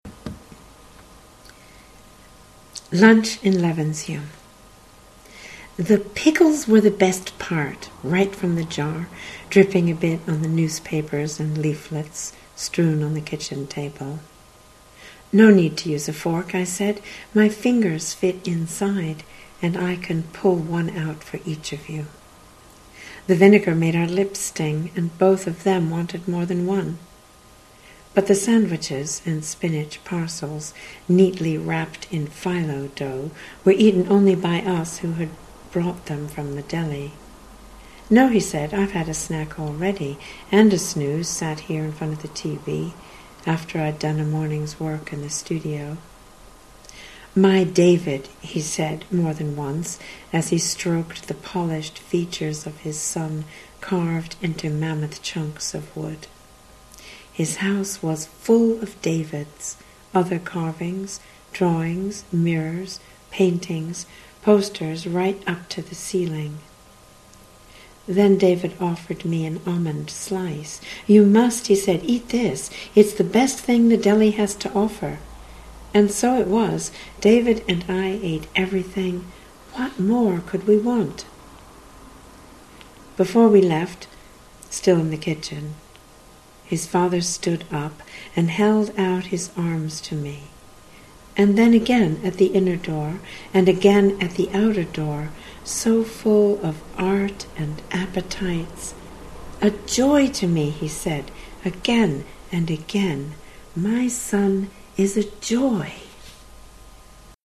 lunch_in_levenshulme.mp3